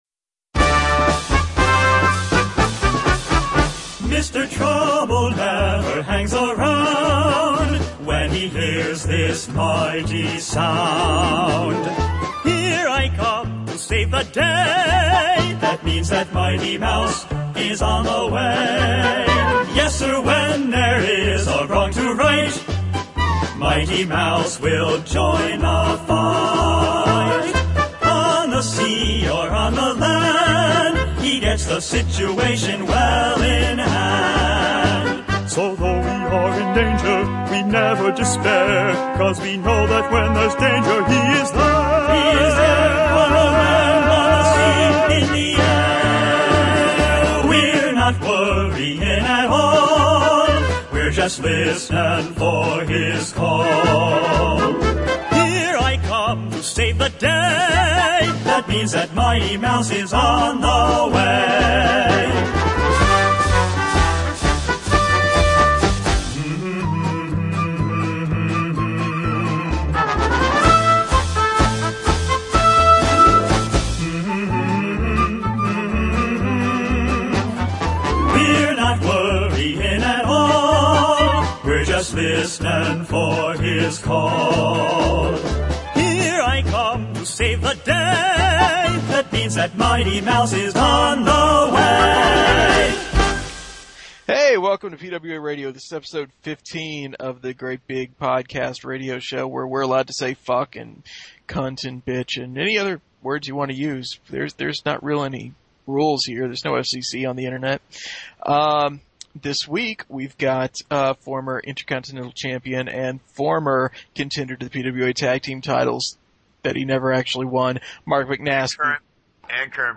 I even made sure the closing song was short for you.